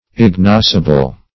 Ignoscible \Ig*nos"ci*ble\